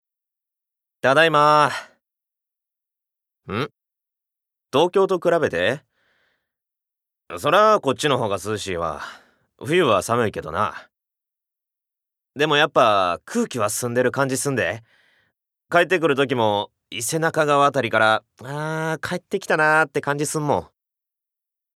Voice Sample
ボイスサンプル
セリフ７